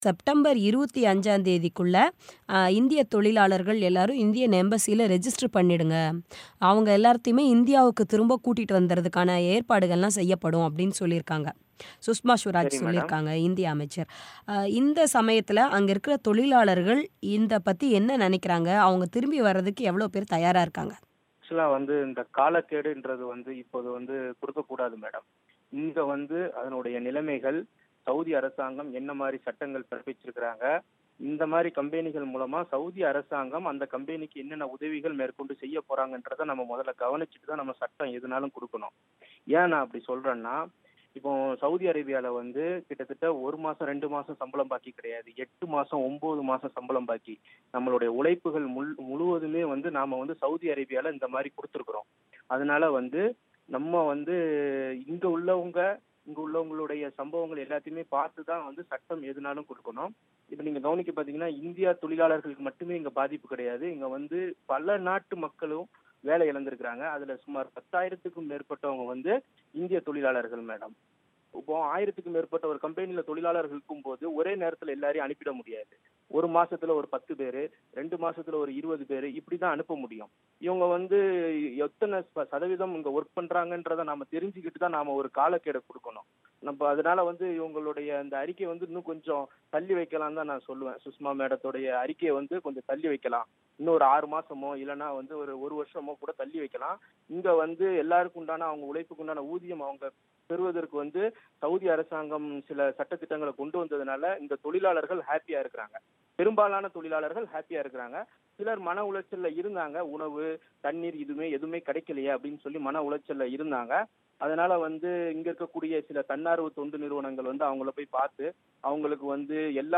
சௌதியில் வேலையிழந்து தவிக்கும் இந்தியர்கள் குறித்த பேட்டி